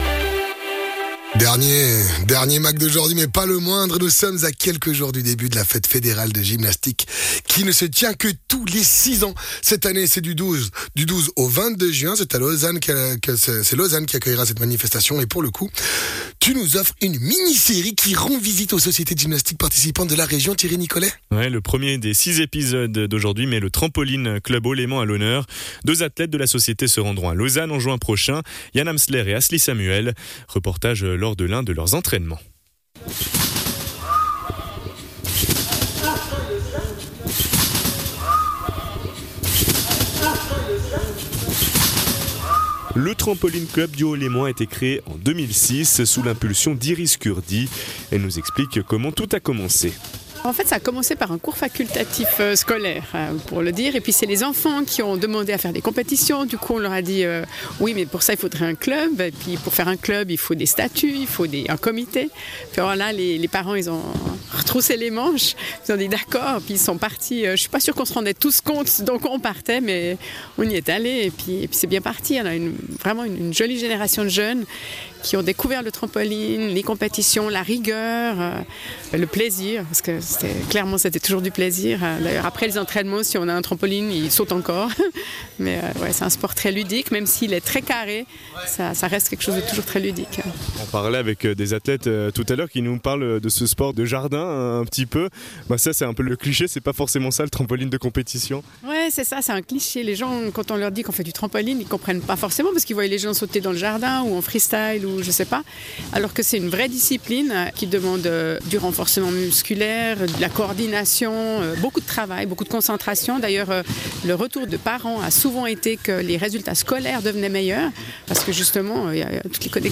Intervenant(e) : Trampoline Club Haut-Léman